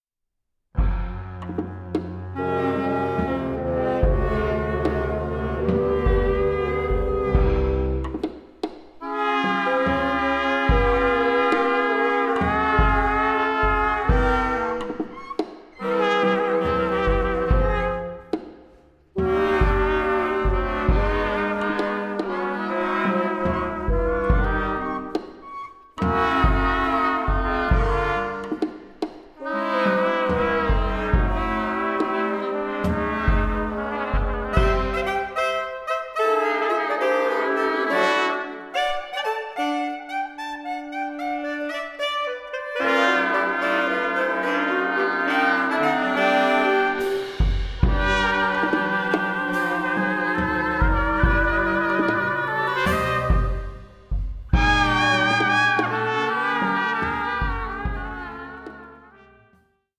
the versatile reed players
the reed quintet.